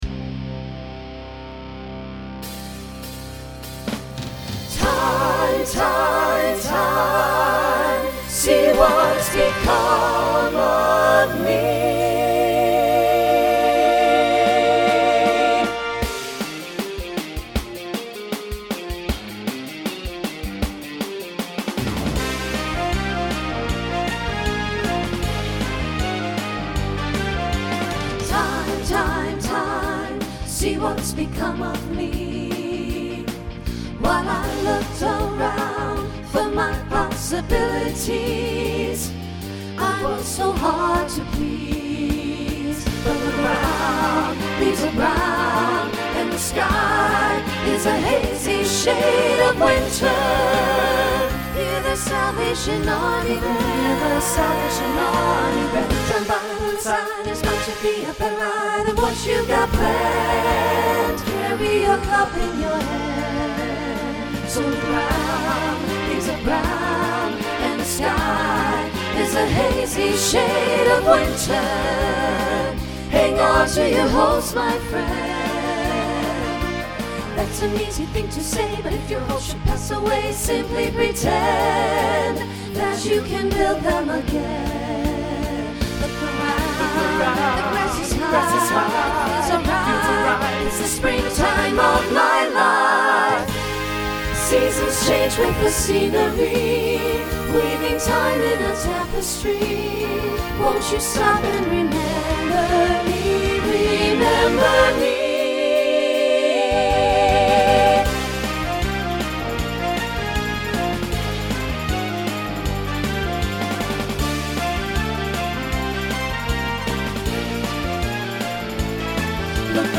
New SSA voicing for 2025.